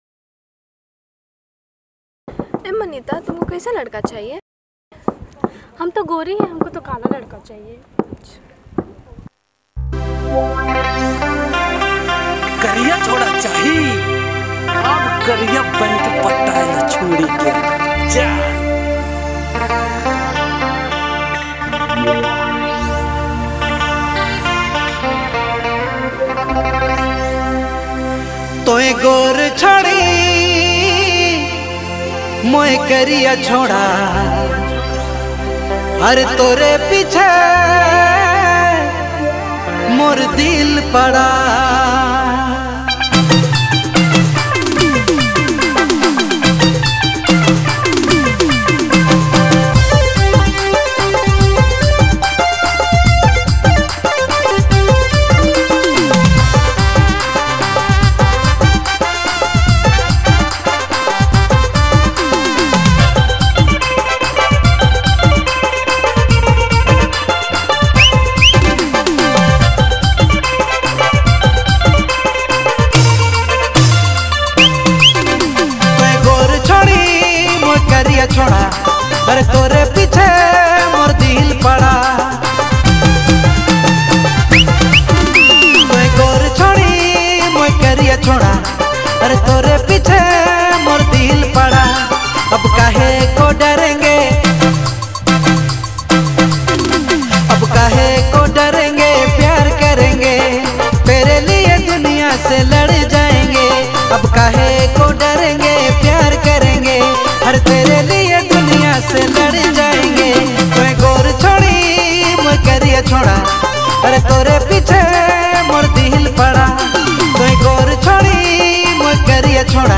All Dj Remix
New Latest Nagpuri Song